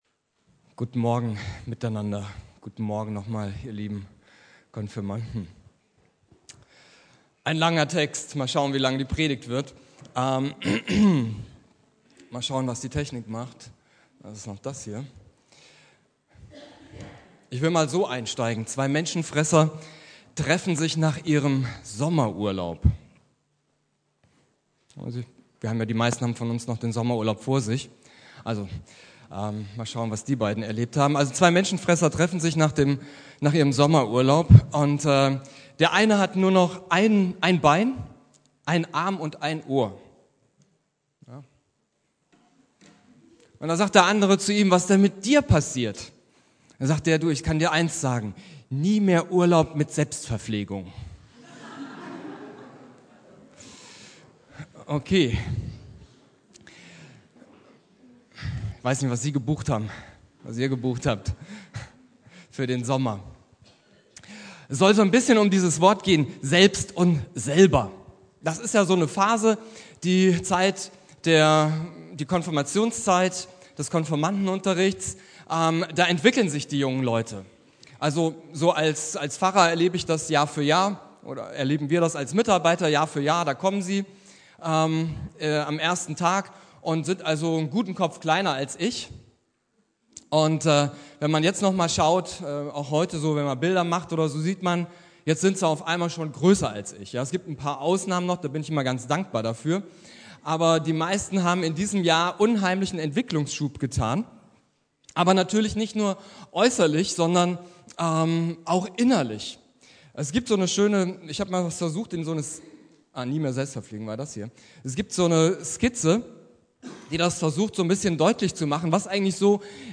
Predigt
Die Frau am Brunnen (Konfirmationsgottesdienst) Bibeltext